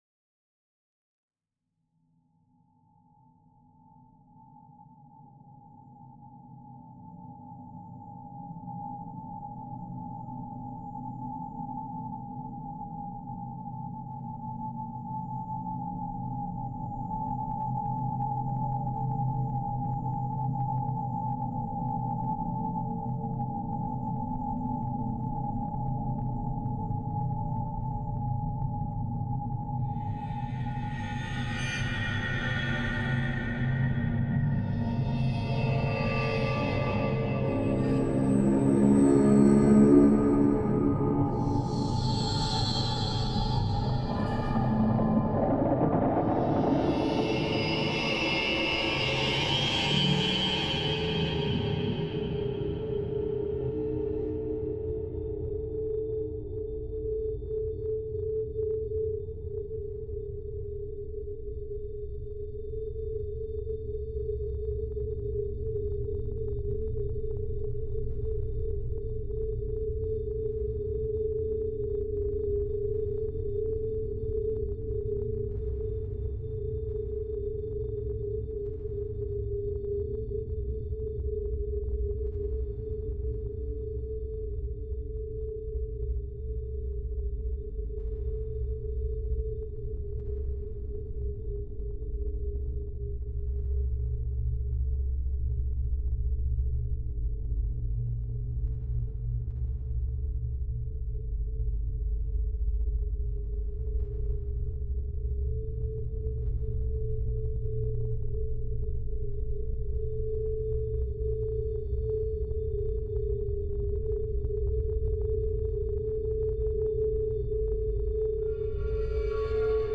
psychological horror story
Here’s a sneak peak at an excerpt from the score.